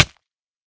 minecraft / sounds / damage / hit3.ogg
hit3.ogg